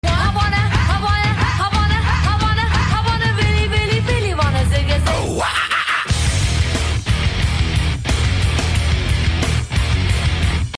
dance
mashup